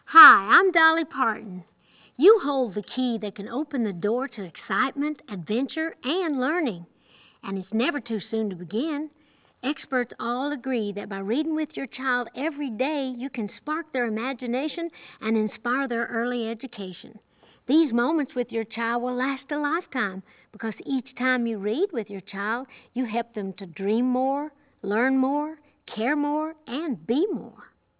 2021_audio_dolly-psa-1-R.wav